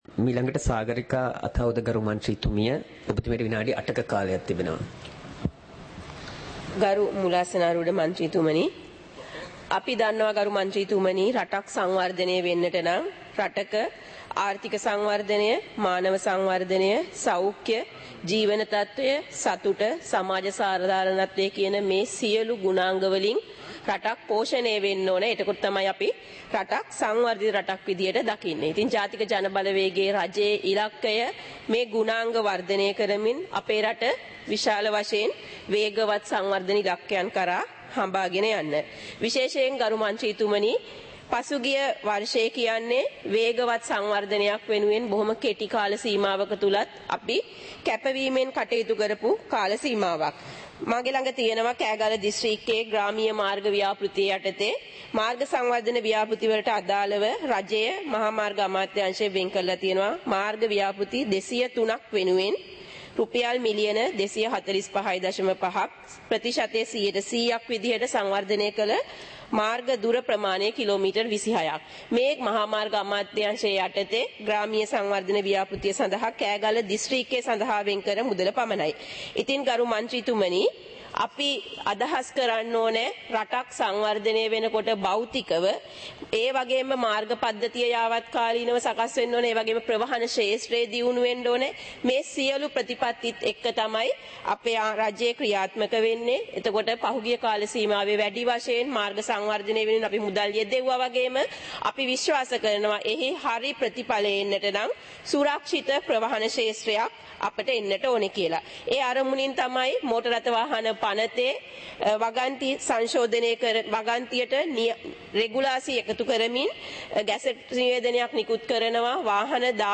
பாராளுமன்ற நடப்பு - பதிவுருத்தப்பட்ட